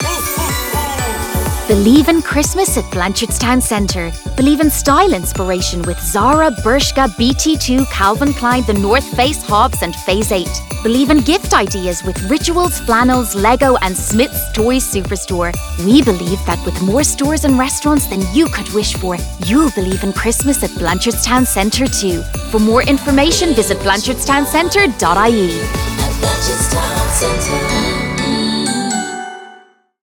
voice-over
Blanchardstown-Main-Brand-Ad-1-30-sec.wav